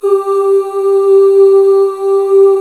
Index of /90_sSampleCDs/Club-50 - Foundations Roland/VOX_xMaleOoz&Ahz/VOX_xMale Ooz 1M